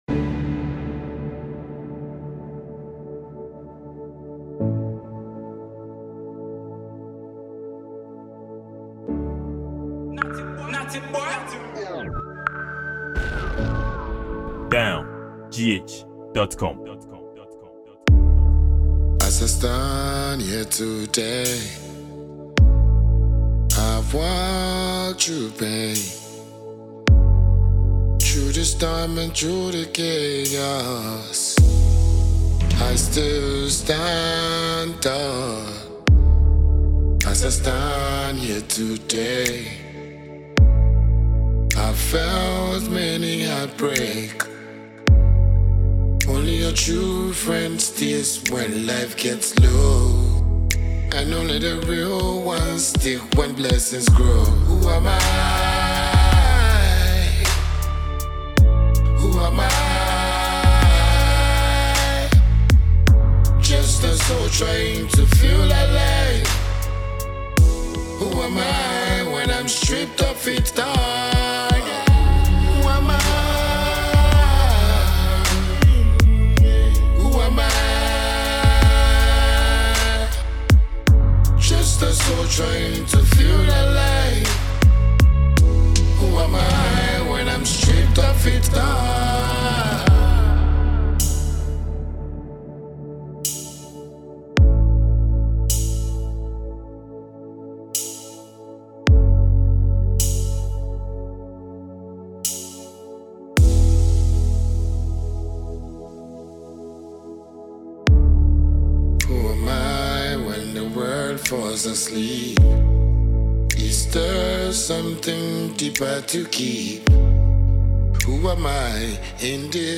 He is a Ghanaian dancehall musician and songwriter.